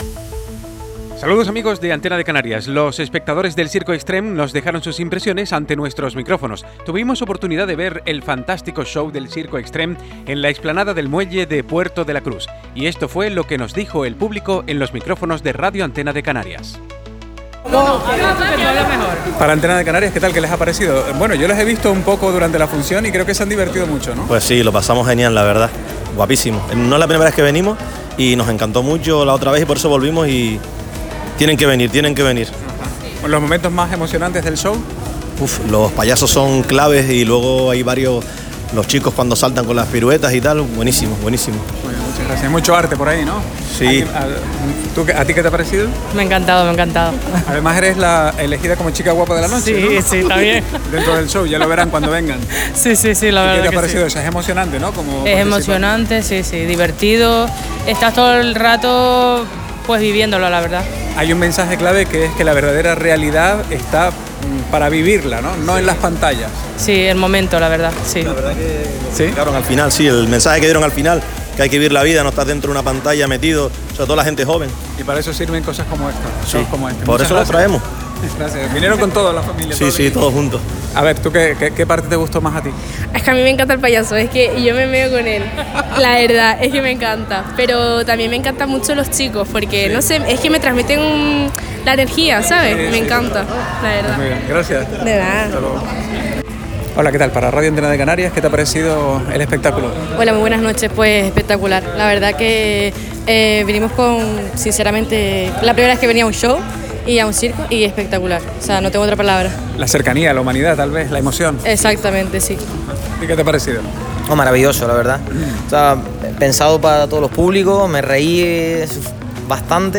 — Estas son las impresiones de los espectadores en Radio Antena de Canarias a la salida del espectáculo de Circo Extreme en Puerto de la Cruz, a la que asistimos:
Opiniones-de-publico-musica.mp3